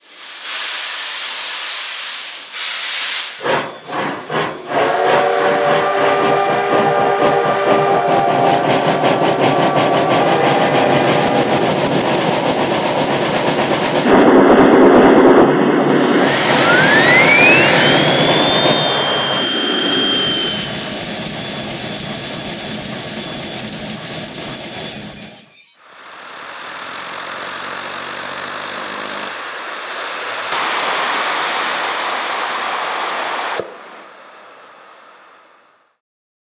H0 1:87 Gleichstrom DC 2L, digital DCC/mfx+/MM mit Sound.
TRIX 25141 Demo-Sound.mp3